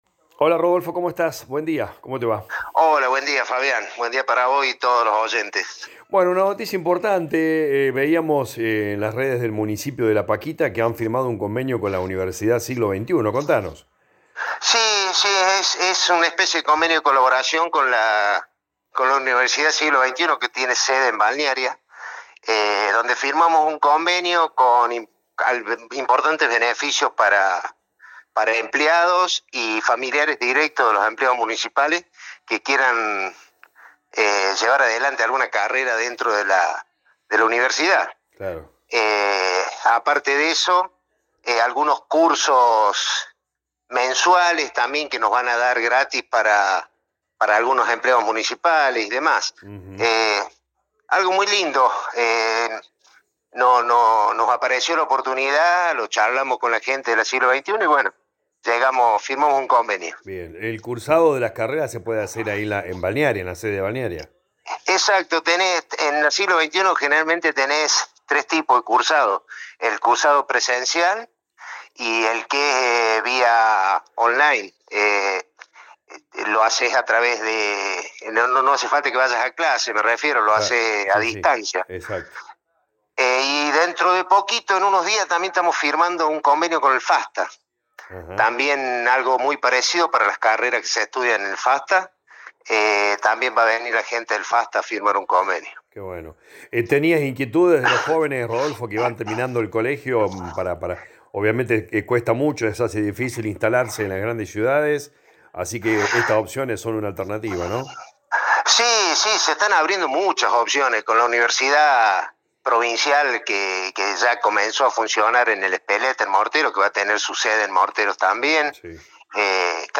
En diálogo con LA RADIO 102.9 FM el intendente de la localidad de La Paquita Rodolfo Musello anticipó que están trabajando en distintas obras en la localidad.